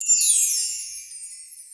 Windchi.wav